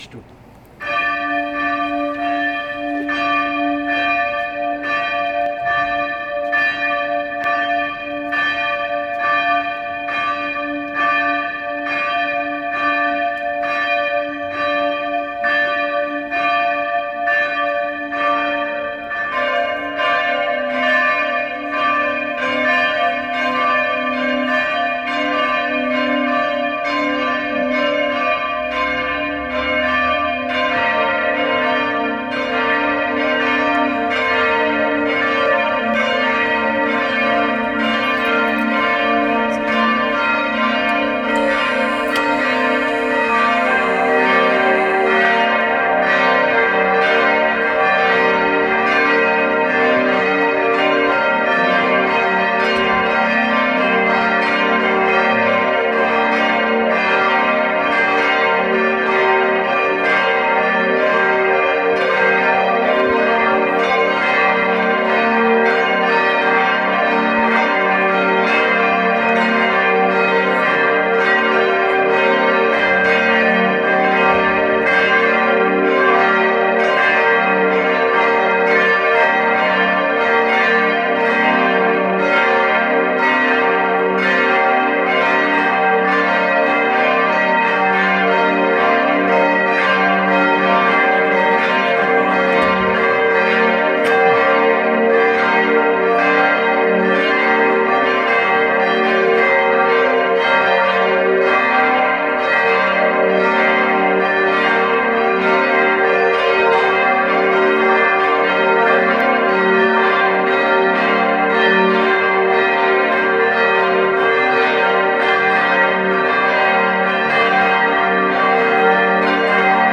Zvonění - evangelický kostel U Salvátora Ohláška sboru, církvi a městuV úterý 31. října 2017 se na...
zvony.mp3